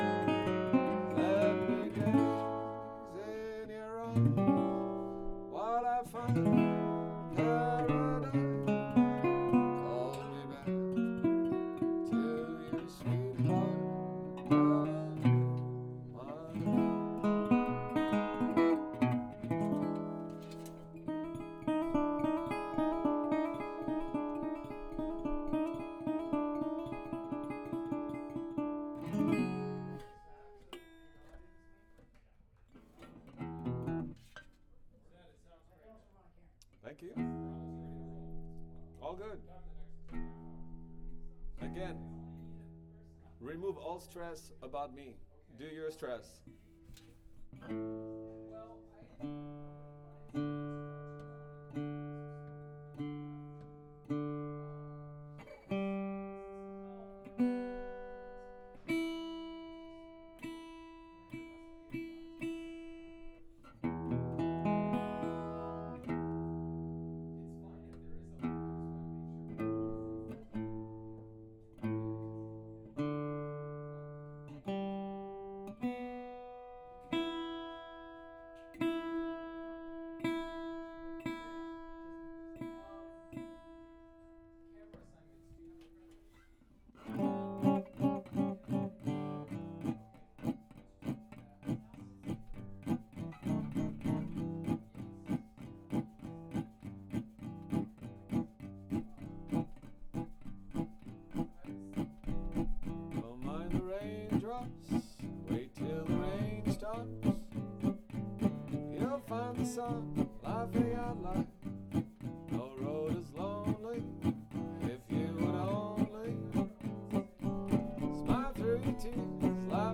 GTR SCHOEPS_01.wav